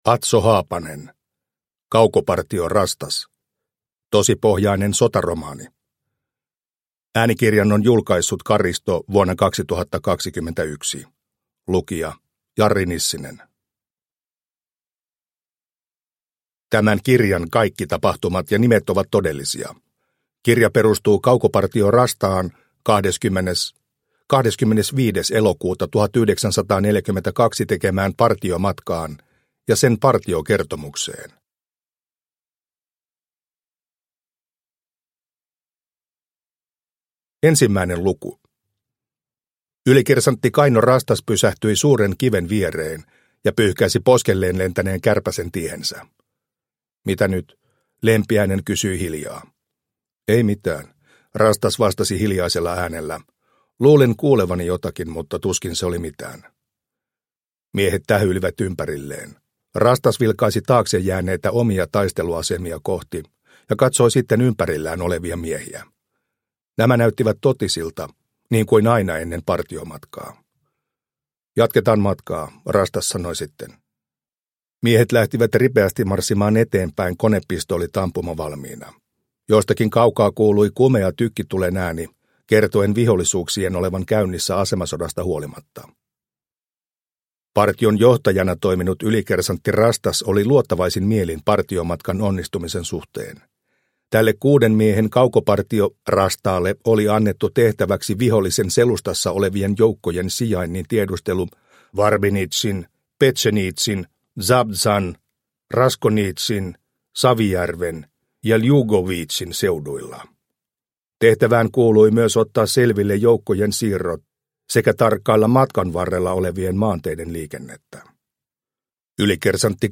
Kaukopartio Rastas – Ljudbok – Laddas ner